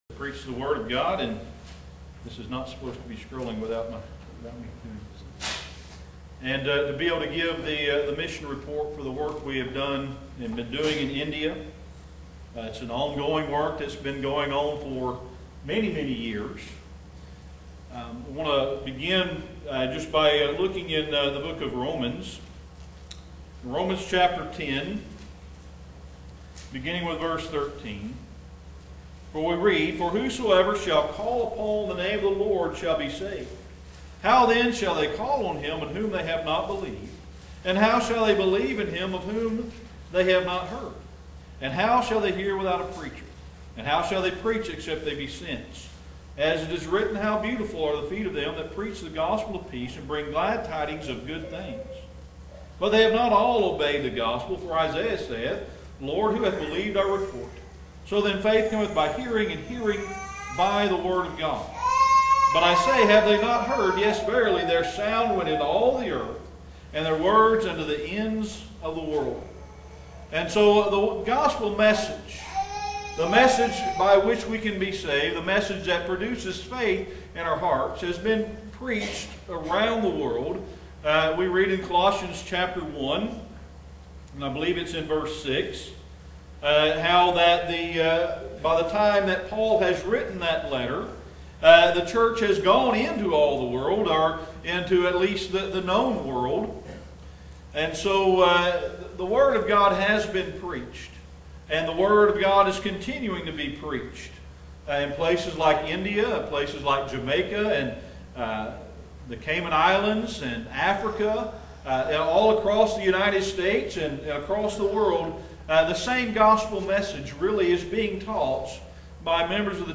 Listen to the report given at Oak Grove church of Christ on February 28, 2016 HERE.